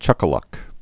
(chŭkə-lŭk)